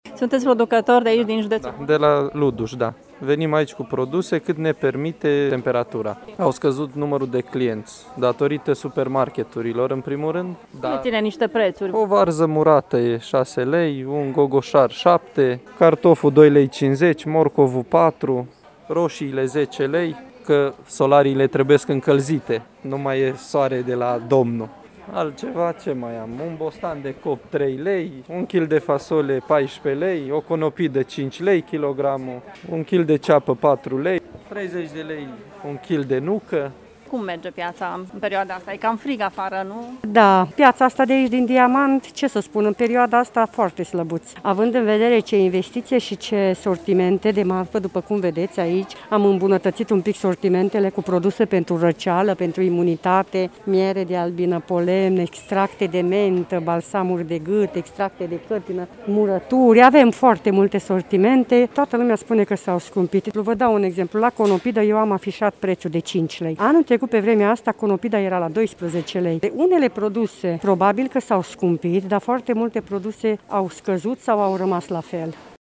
Vânzătorii spun, însă, că prețul unor legume este chiar mai mic decât anul trecut: